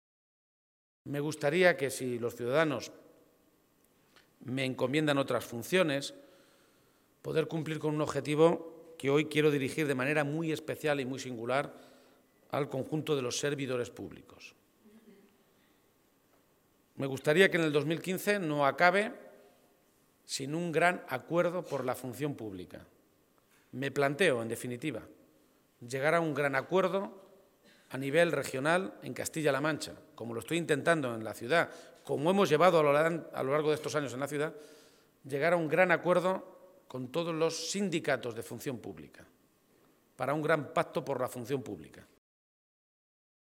García-Page se pronunciaba de esta manera esta mañana, en una comparecencia, en Toledo, ante los medios de comunicación, en la que hacía balance municipal y regional del año que termina en unos días.
Cortes de audio de la rueda de prensa